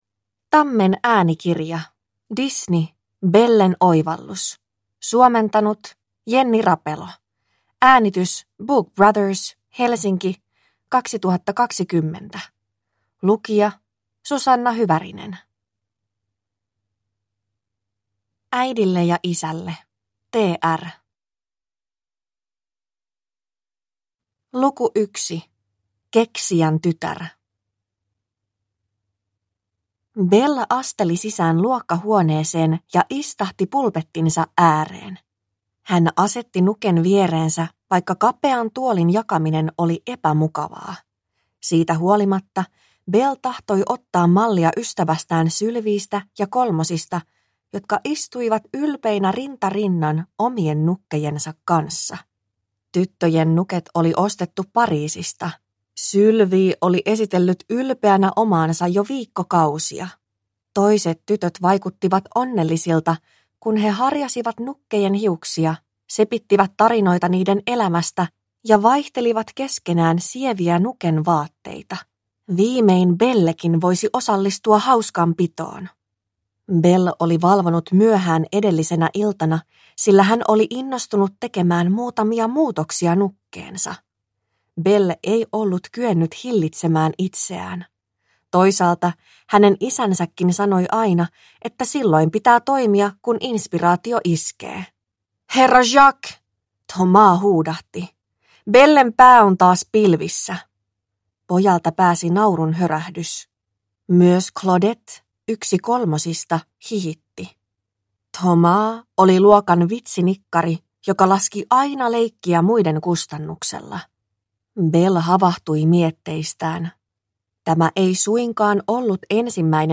Bellen oivallus – Ljudbok – Laddas ner